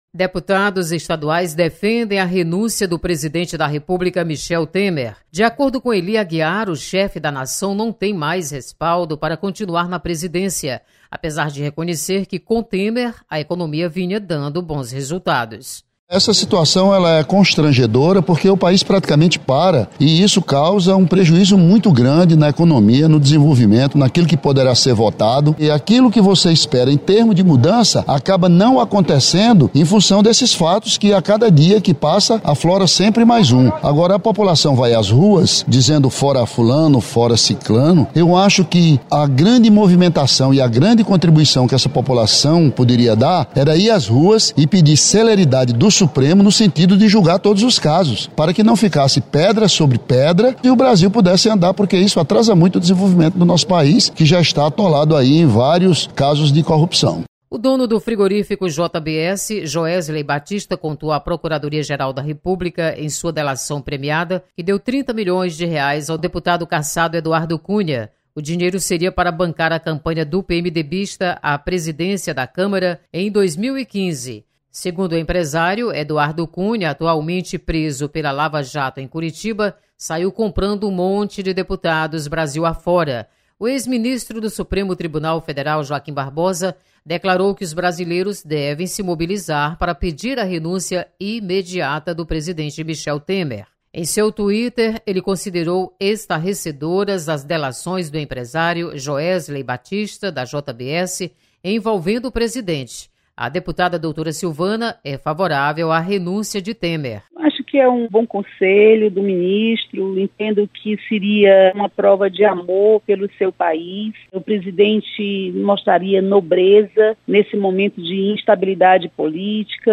Deputados comentam sobre situação política do país e se posicionam quanto ao afastamento do presidente Michel Temer.